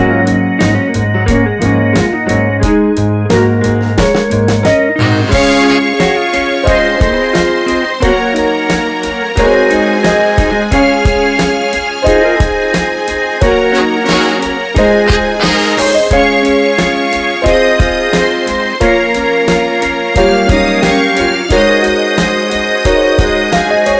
No Bass Or Backing Vocals Soul / Motown 4:31 Buy £1.50